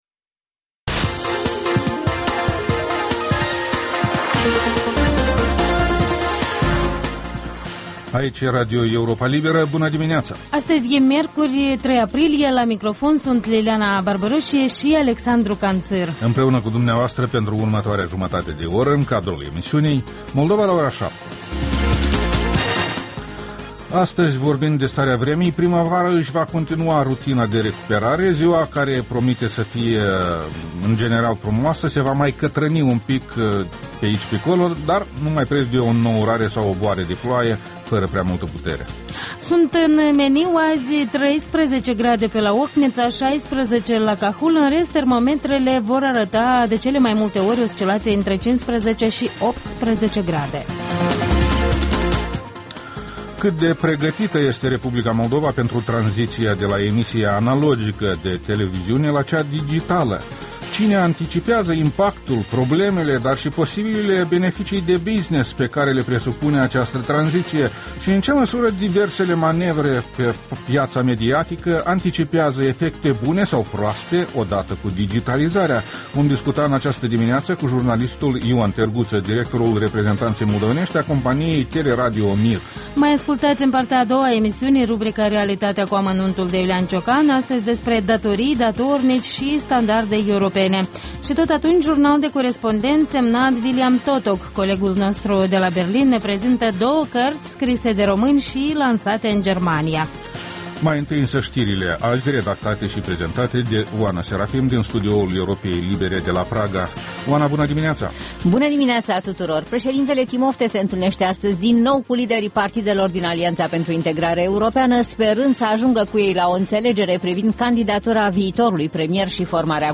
Ştiri, interviuri, analize.